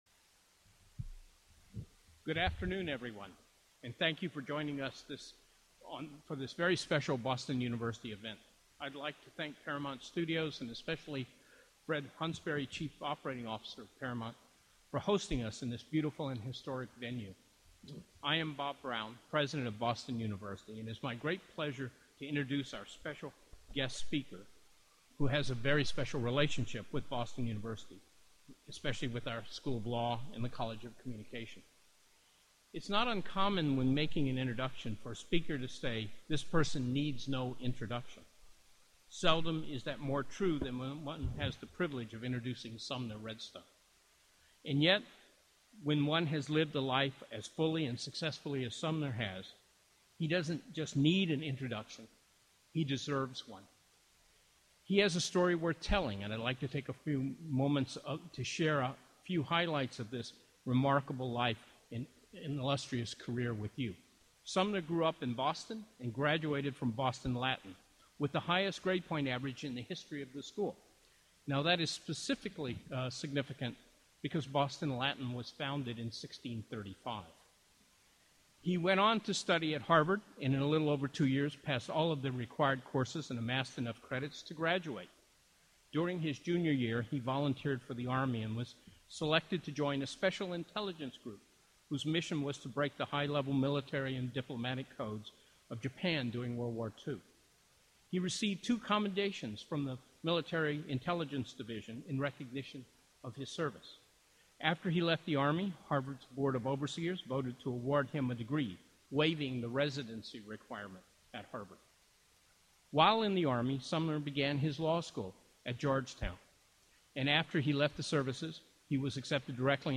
Listen to Mr. Redstone’s speech and subsequent Q&A. /com/files/2015/10/redstoneaddress.mp3
Executive Chairman of Viacom, Inc. and CBS Corporation and former LAW professor Sumner Redstone addressed the BU community in Los Angeles on Tuesday, November 27, 2007, at Paramount Studios.
redstoneaddress.mp3